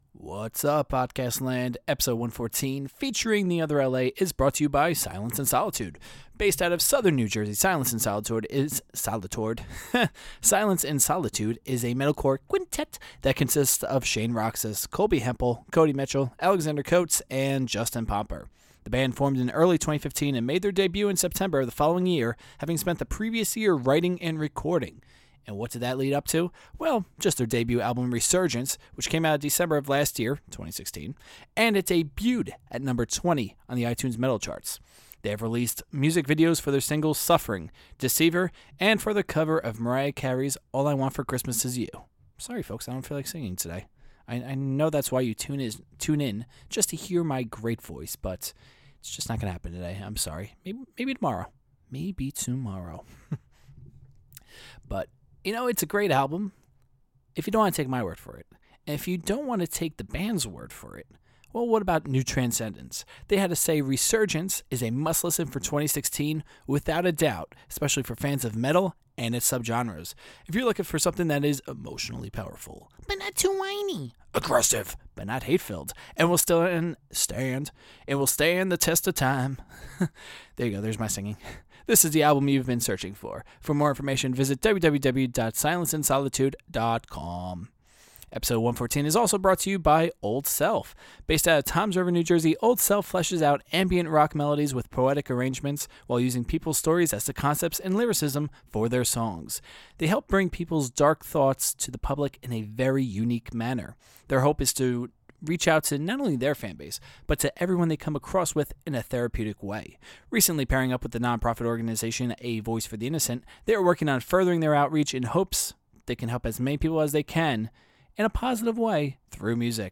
Post-Interview Song: Hallucinations